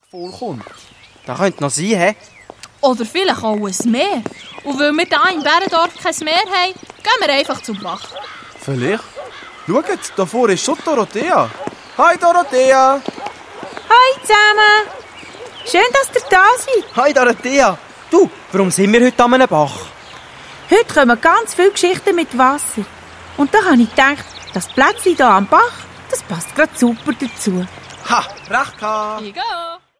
Hörspiel-Album
mit vielen Sprechern gespielt